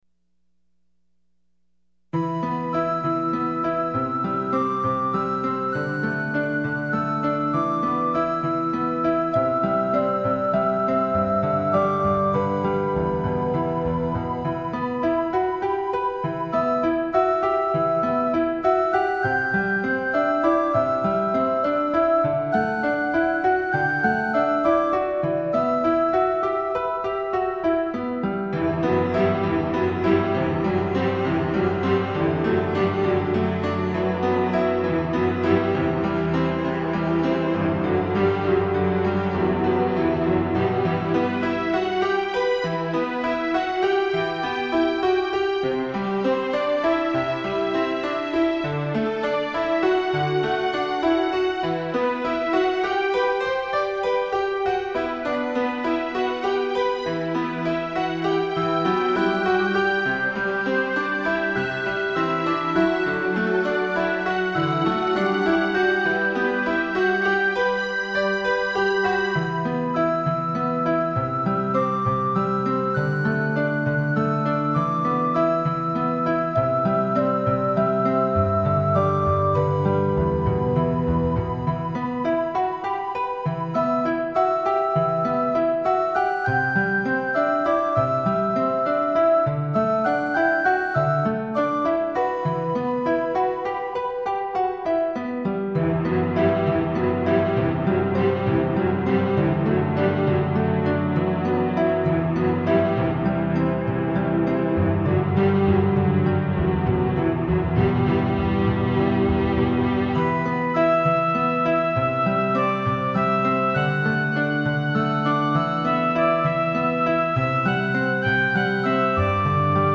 Never Mind 경험부족과 모듈이 별로 안좋습니다. 그래서 아직은 음악이 매우 단조롭다는 평이 많았습니다.